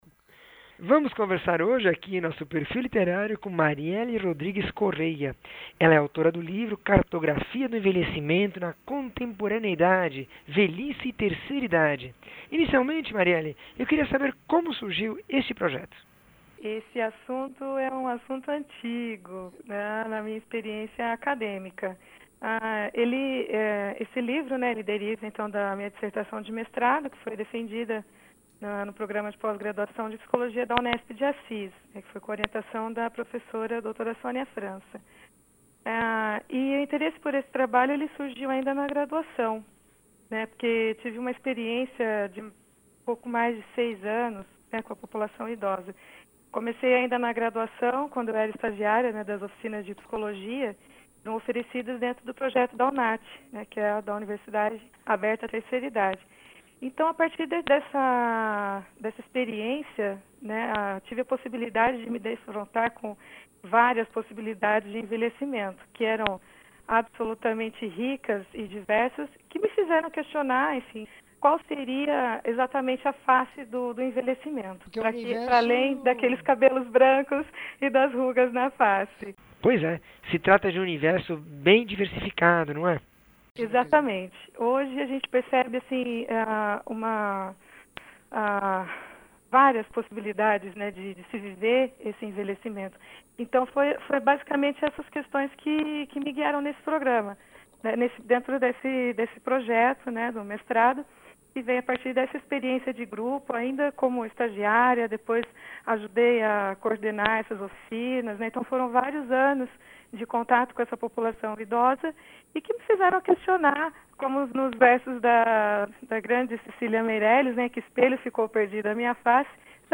entrevista 664